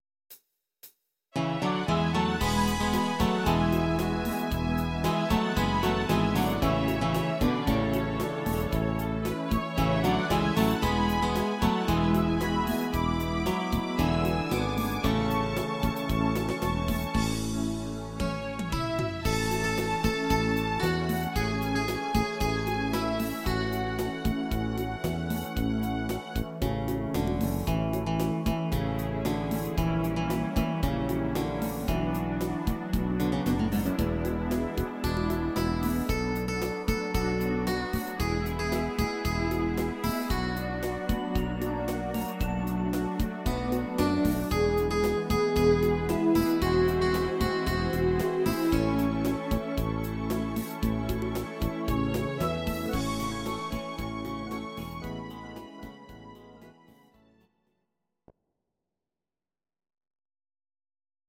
Audio Recordings based on Midi-files
Instrumental